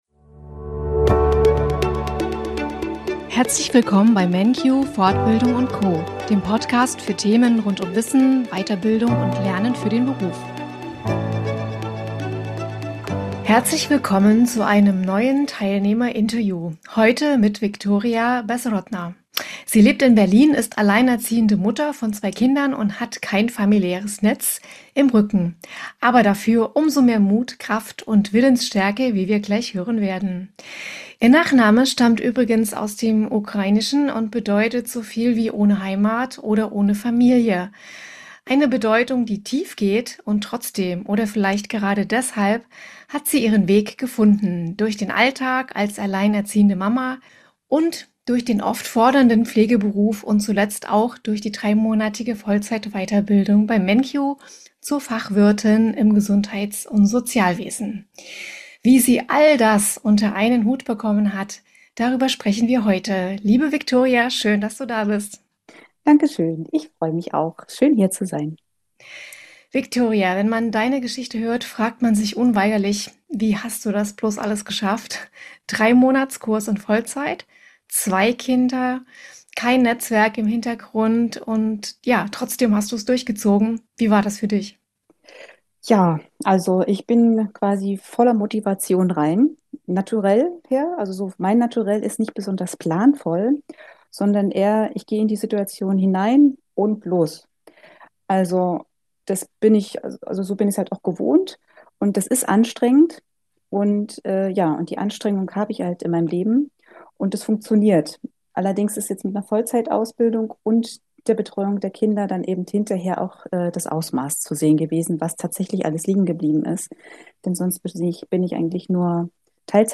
Ein Gespräch voller Ehrlichkeit, Motivation und einer guten Portion Augenzwinkern. Du bist vielleicht auch am Überlegen, ob eine Weiterbildung mit Deiner beruflichen und familiären Situation anzupacken?